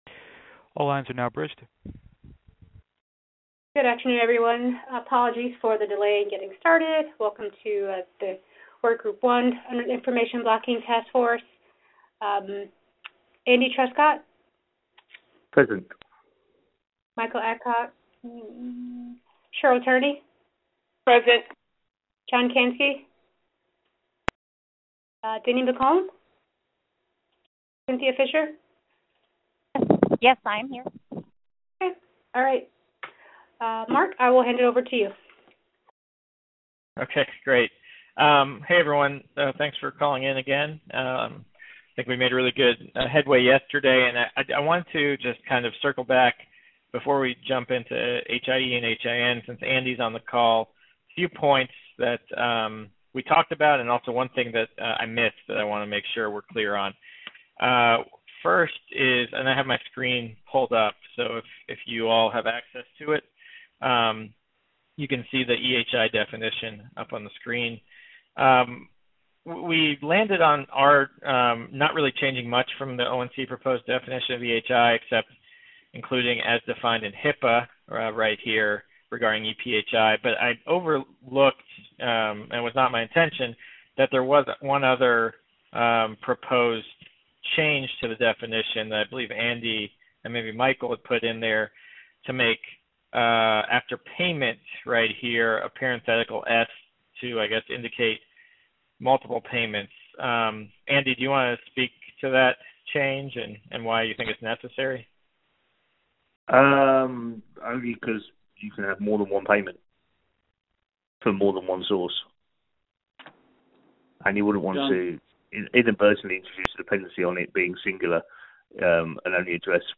2019-03-28_IACC_group1_VirtualMeeting_Audio